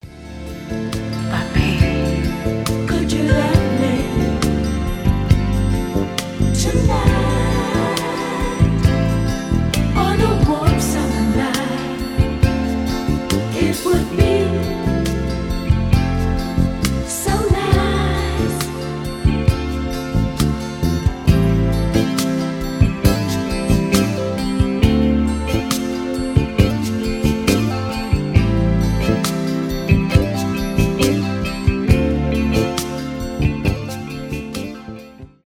поп , соул , зарубежные , баллады
романтические , ретро , 70-е , медленные